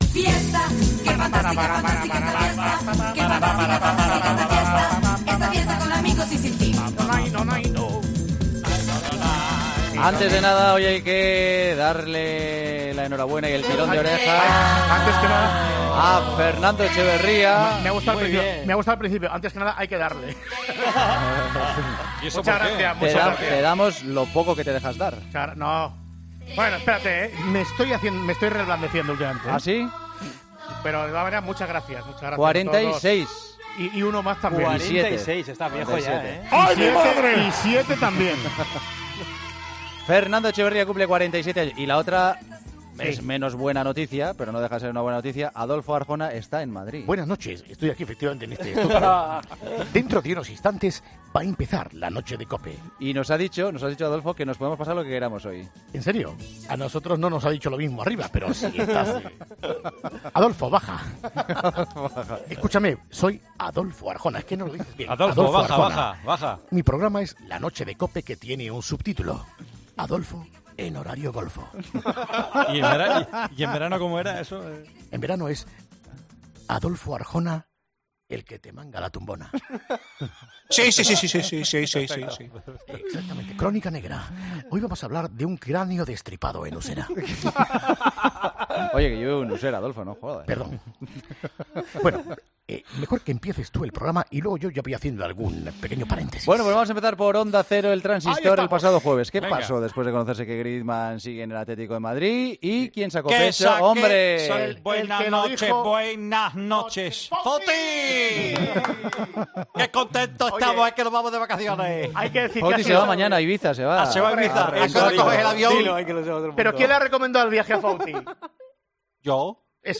Esta semana, conexión de José Ramón de la Morena con México para charlar con un periodista de la selección mexicana.